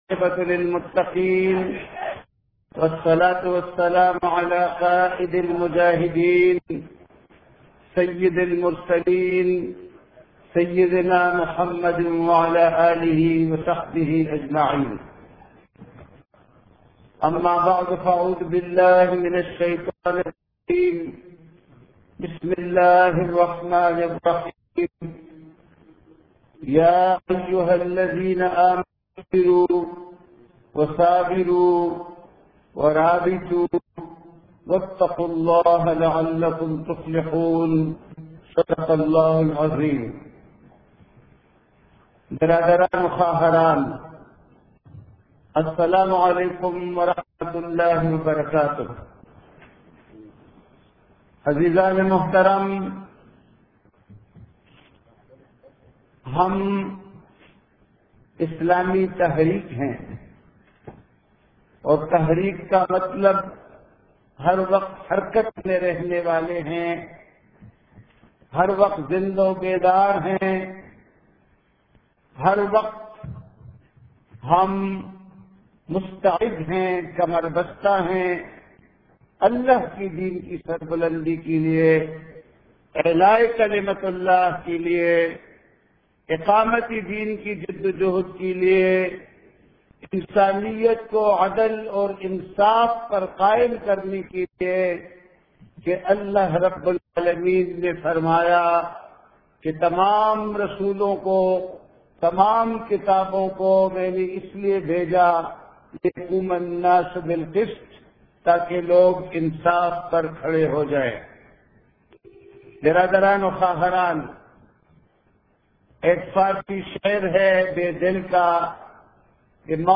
Majoda Almi Aur Qomi Tanazur Main Hamara Ayenda Ka Lahe Amal - Ijtama 2007